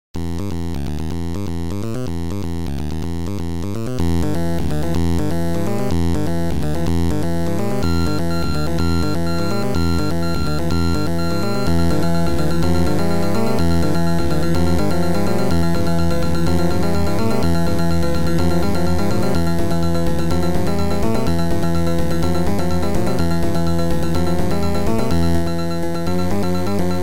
Chip Music Pack